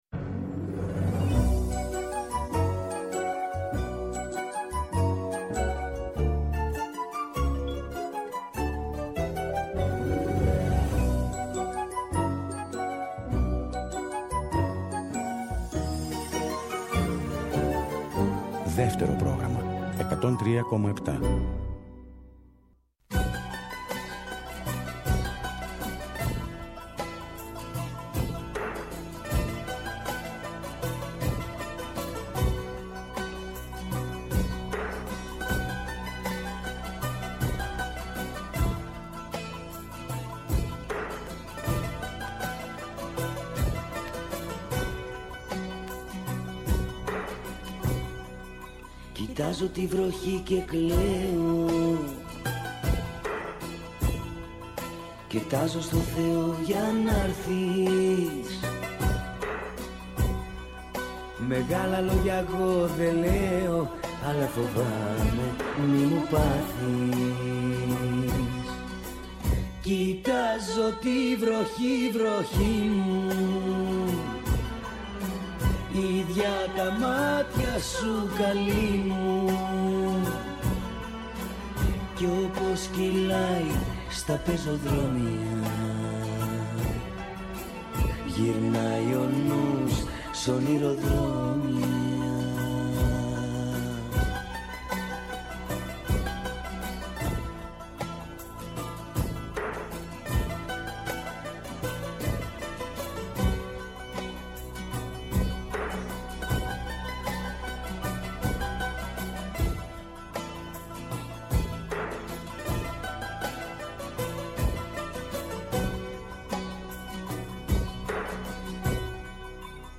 «Άλλη μια μέρα» στον αέρα του Δεύτερου, εκπομπή καλής διάθεσης και μουσικής, για την ώρα που η μέρα φεύγει και η ένταση της μέρας αναζητά την ξεκούραση και τη χαρά της παρέας.